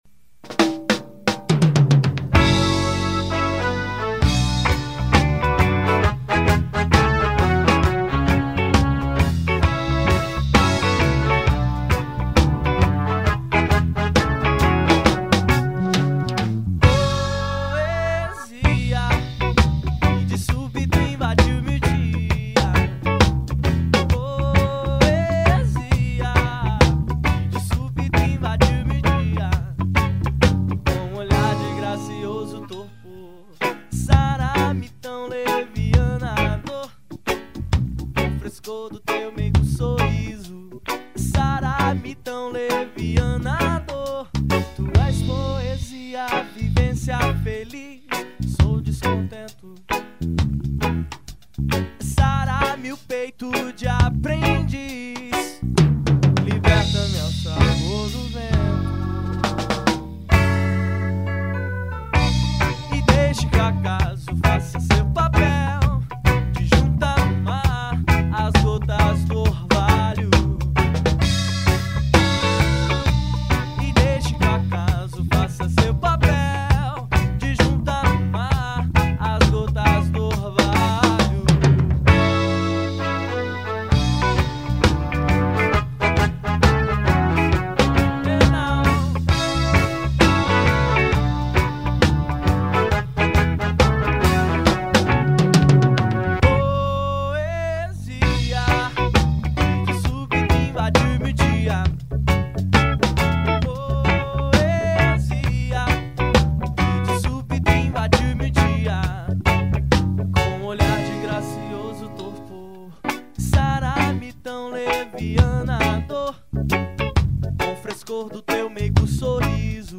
1994   03:13:00   Faixa:     Reggae